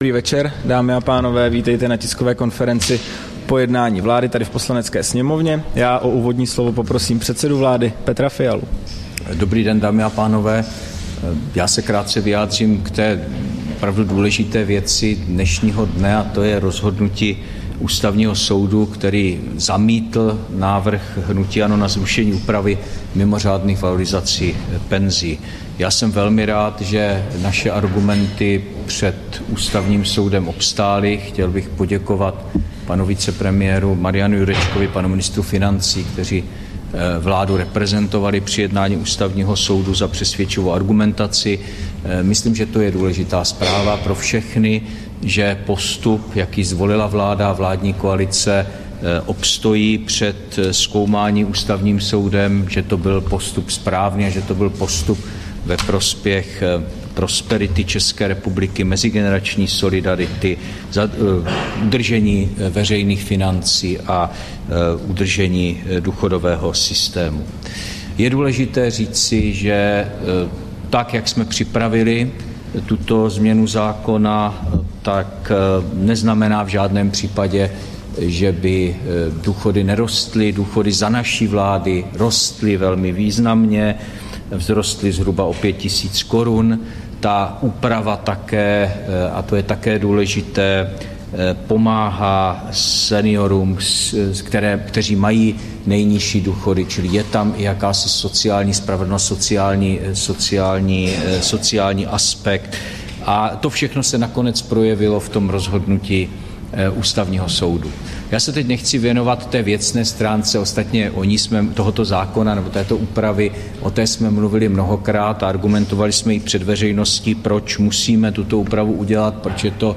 Tisková konference po jednání vlády, 24. ledna 2024